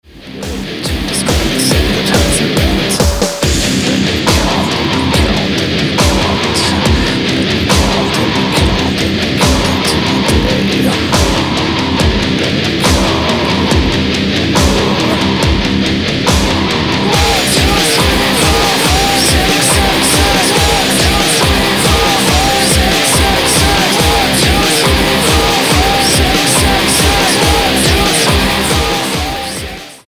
Industrial, Metal